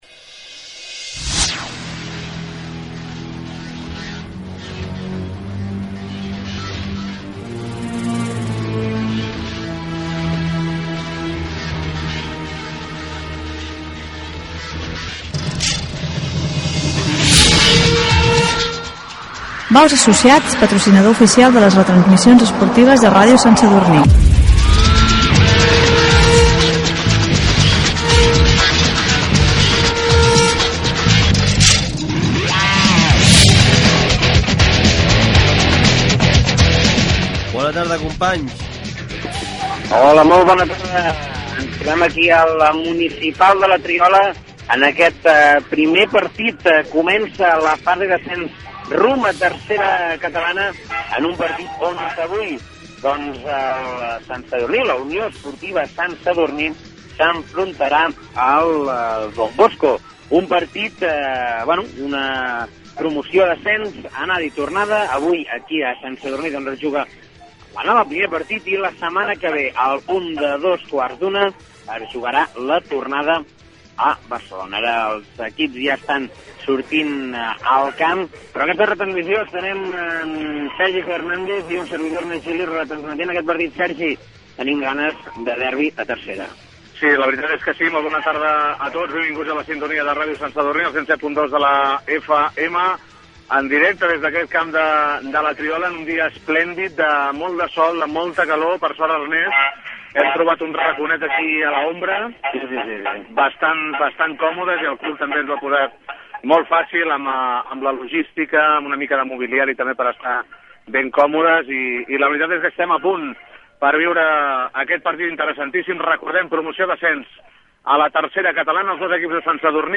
Publicitat, primers minuts de la retransmissió de futbol masculí. Primer partit de la promoció d'ascens a 3ª Catalana entre la Unió Esportiva Sant Sadurní i Club de Futbol Don Bosco (Barcelona). Narració de les primeres jugades.
Esportiu